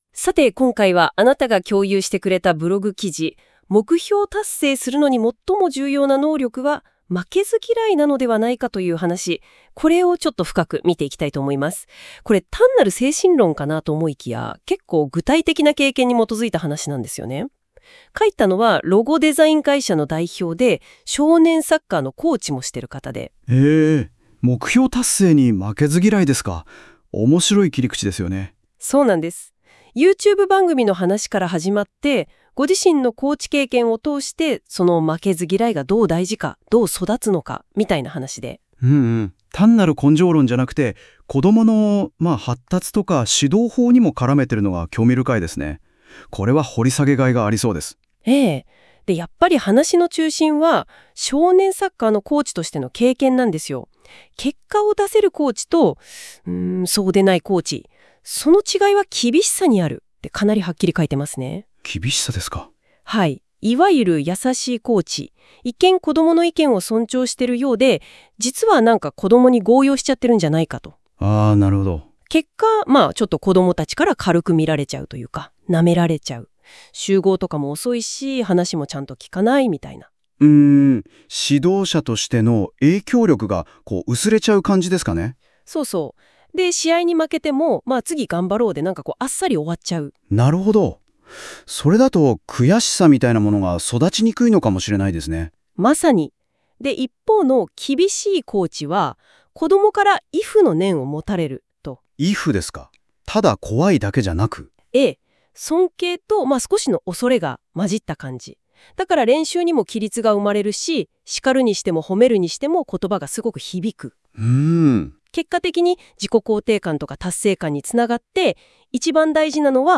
また、このコラムの内容をAIが要約してしゃべってくれる音声ファイルもあわせてアップしますね。